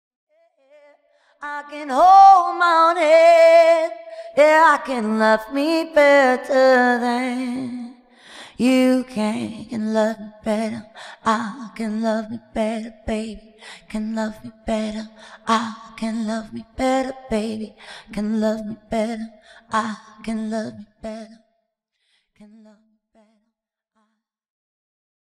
DEMO OF THE LV :